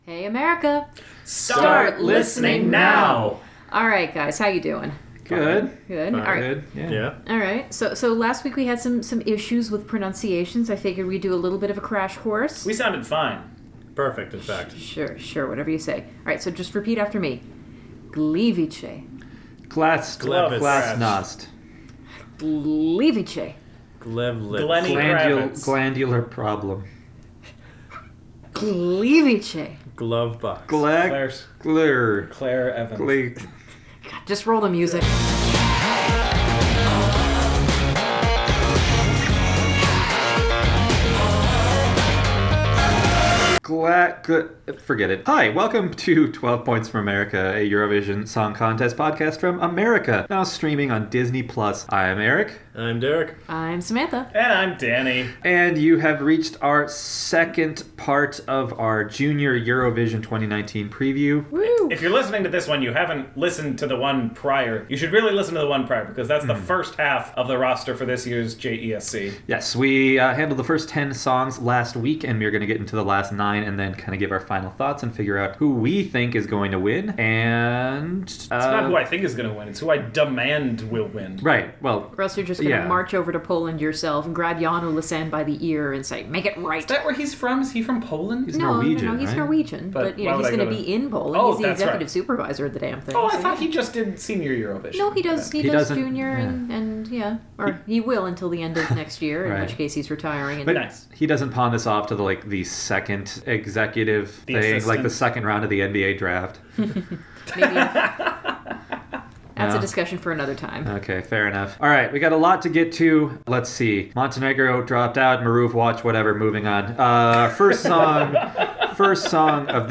In this episode, the 12 Points team checks out the rest of this year’s songs, and we make our final predictions for who will take home this year’s crown. Also featuring, for some reason, a short-lived, futile attempt at Polish pronunciation.